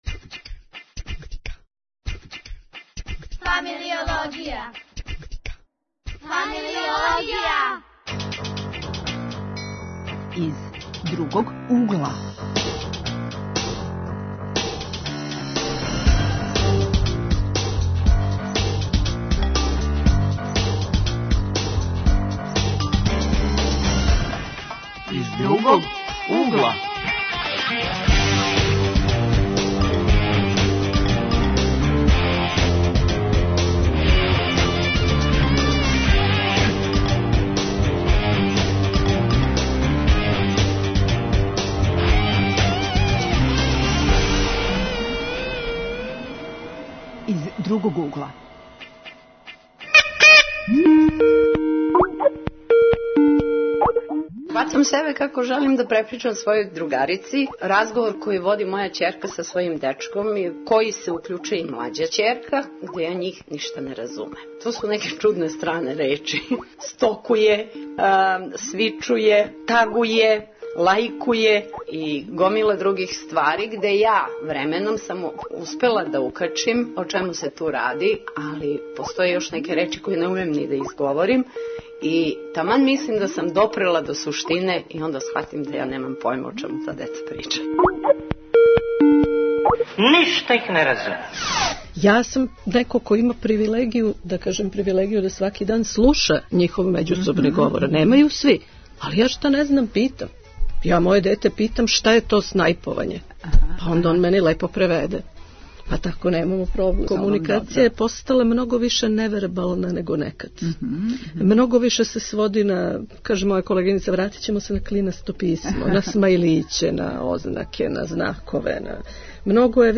Гости у студију су студенти.